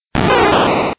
P3D-Legacy / P3D / Content / Sounds / Cries / 1.wav